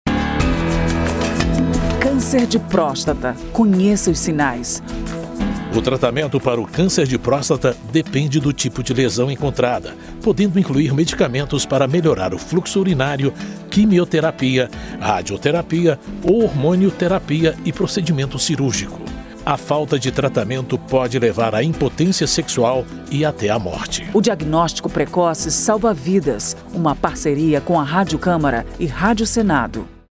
spot-cancer-de-prostata-05-parceiras.mp3